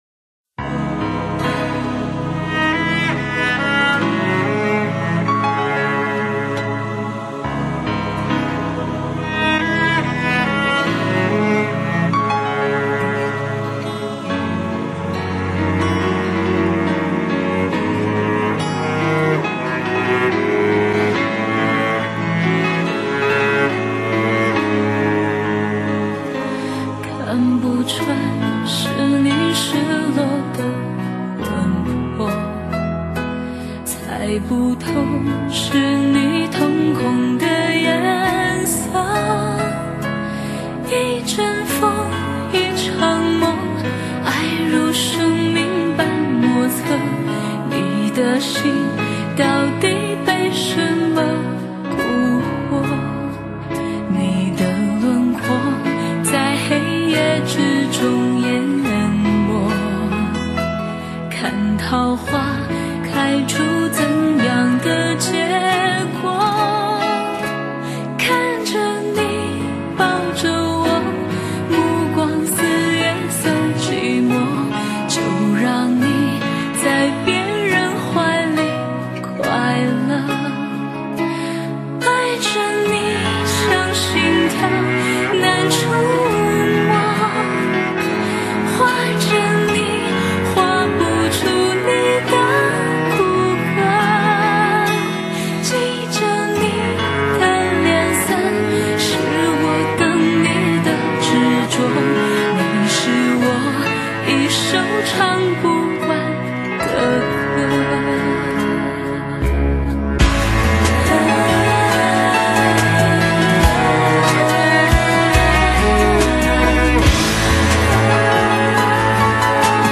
音樂檔（將約 2 分 18 秒開始至 2 分 49 秒處的高音部份消去）。